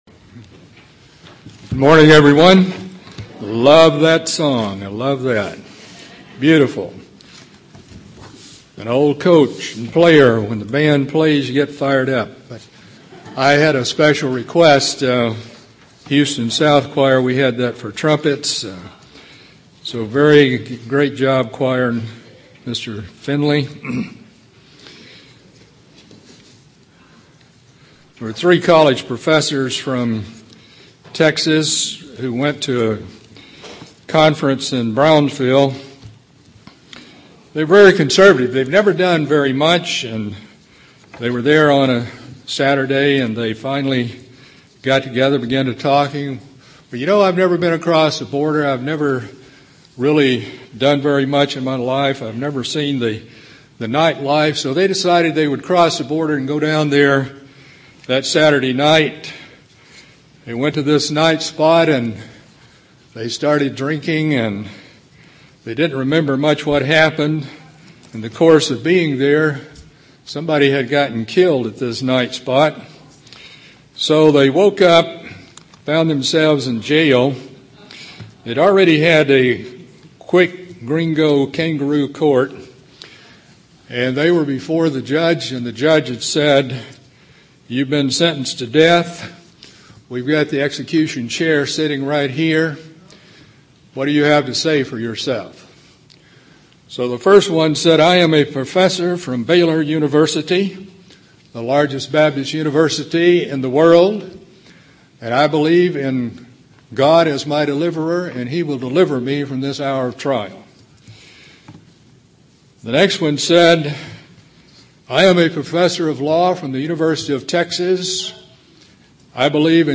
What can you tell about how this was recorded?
This sermon was given at the Kerrville, Texas 2009 Feast site.